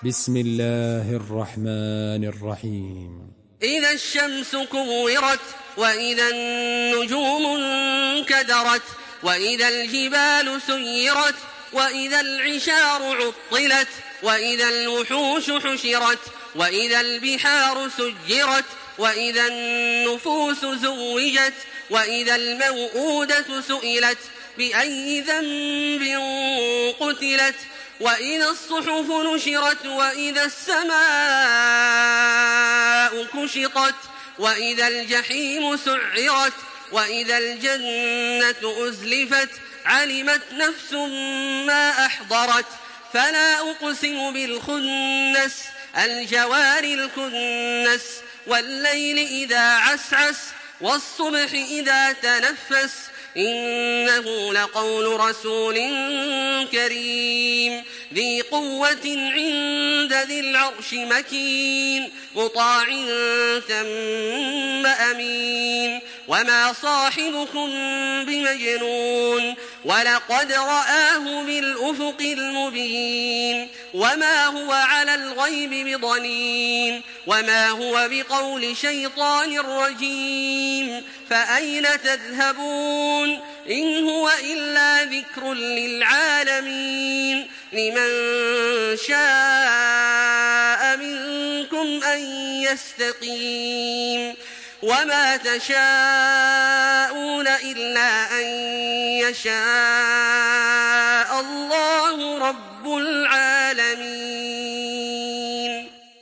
Surah আত-তাকভীর MP3 by Makkah Taraweeh 1426 in Hafs An Asim narration.
Murattal Hafs An Asim